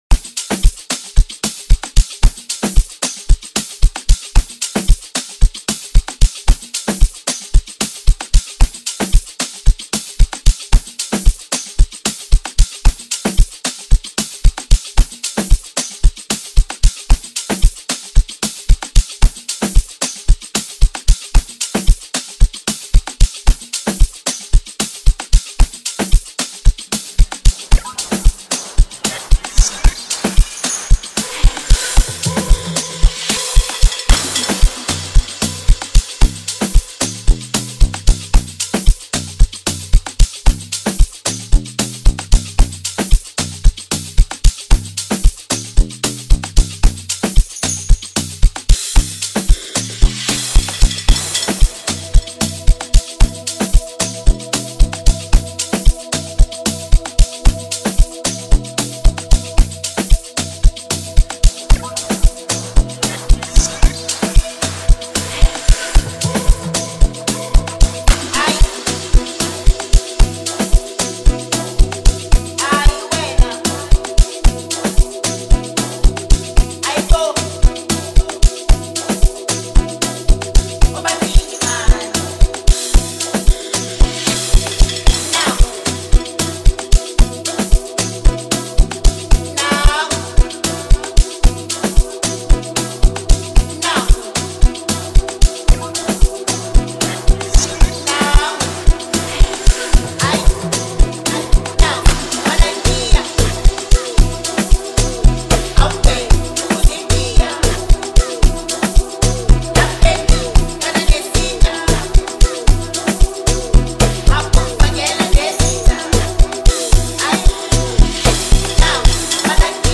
Home » Amapiano » DJ Mix » Hip Hop
is an elegantly arranged piece